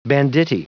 Prononciation du mot banditti en anglais (fichier audio)
Prononciation du mot : banditti